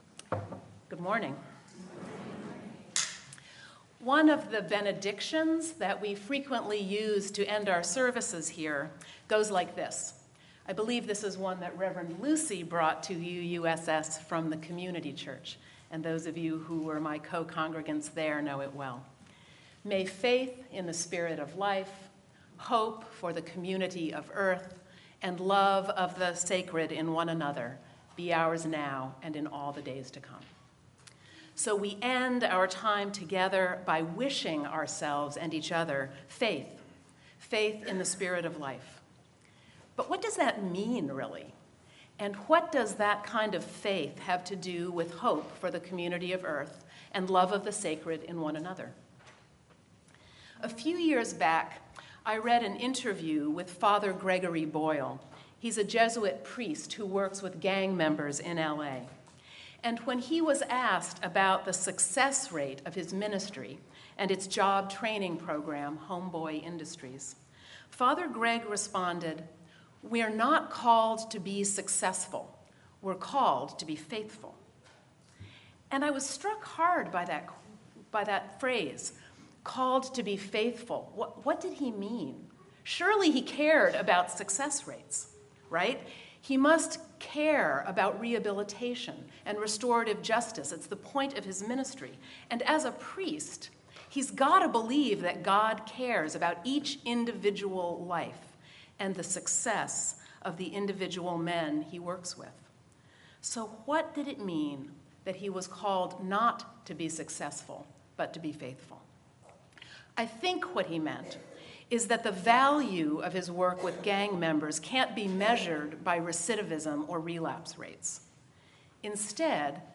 Sermon-Living-Faithfully.mp3